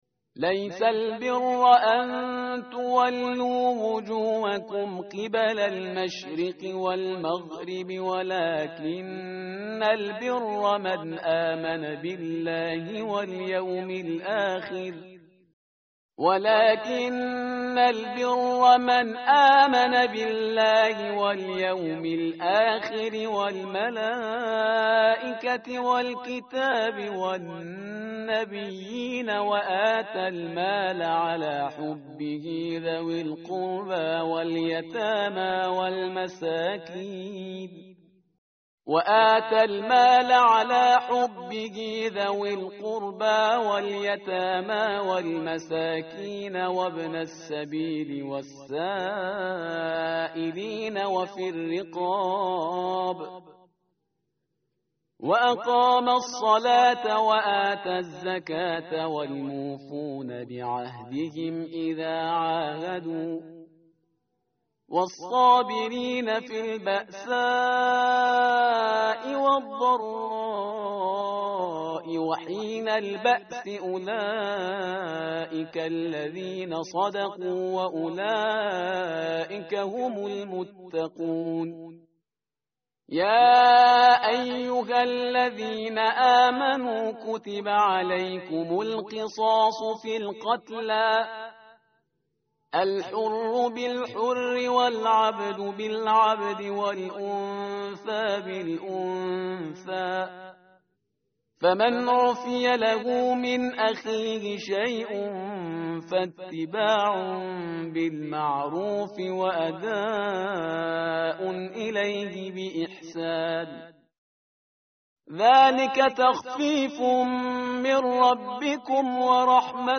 tartil_parhizgar_page_027.mp3